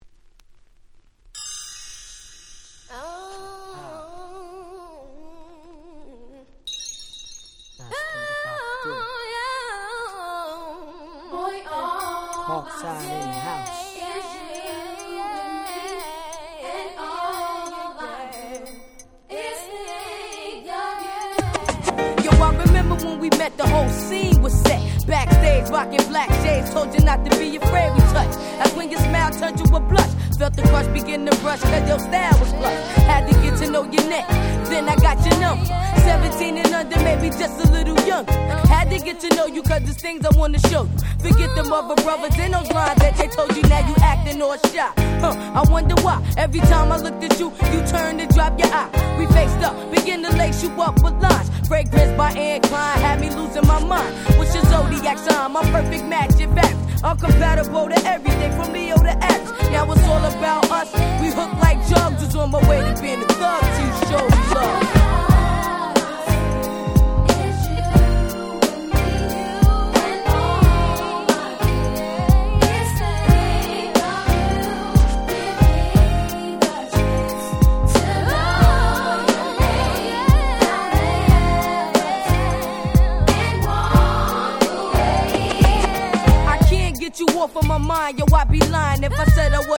96' Smash Hit Hip Hop.